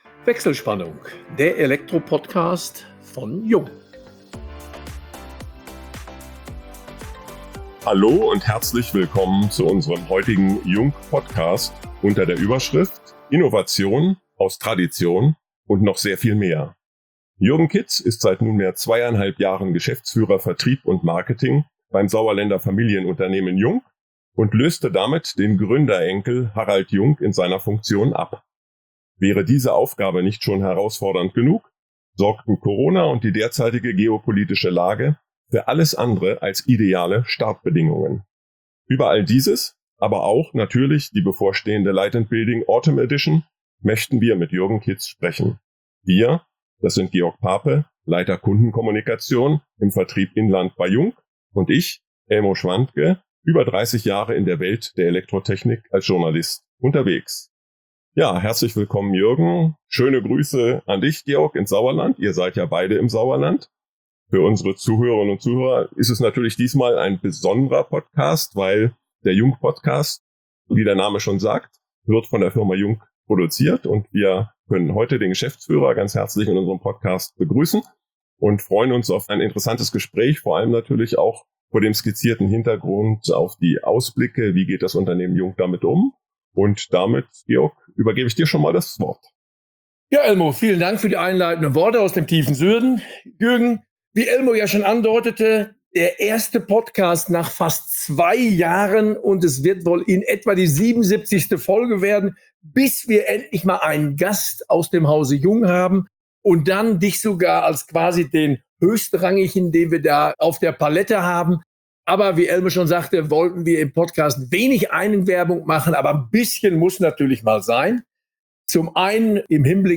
In vertrauensvollen Gesprächen diskutieren die Moderatoren
mit Menschen aus der Elektrobranche.